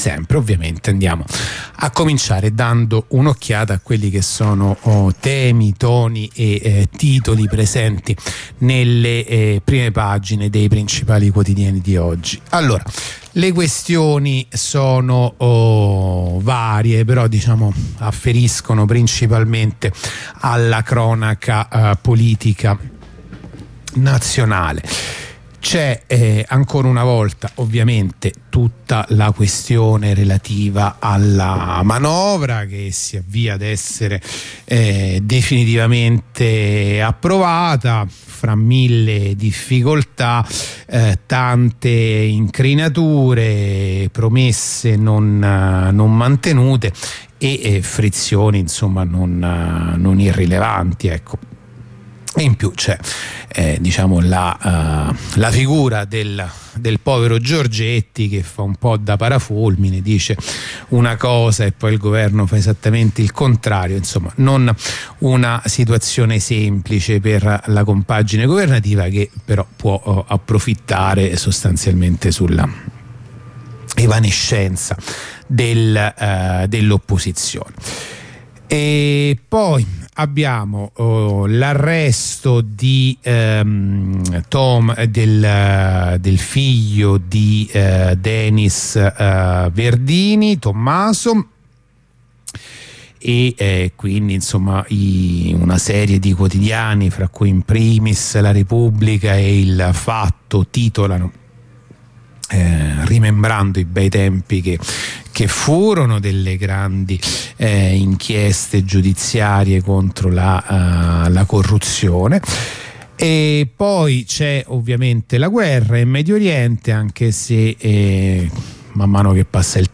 La rassegna stampa di radio onda rossa andata in onda venerdì 29 dicembre 2023